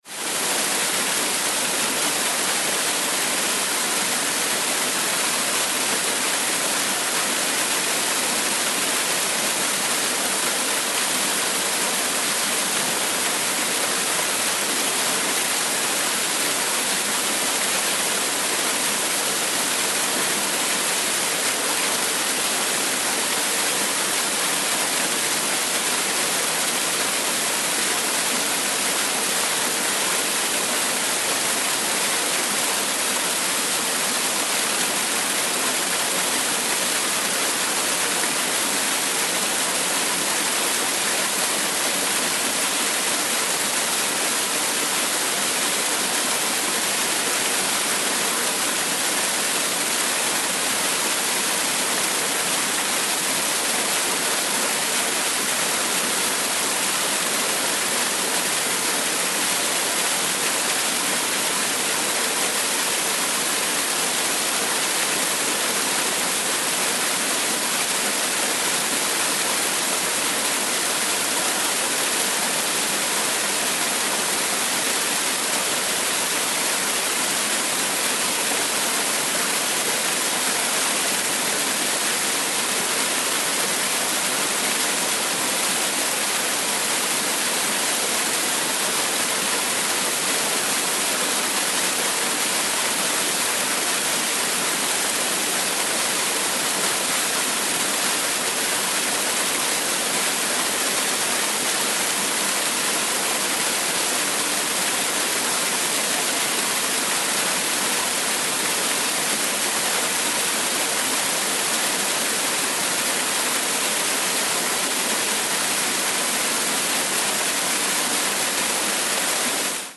Звуки водопада
Шум тропического водопада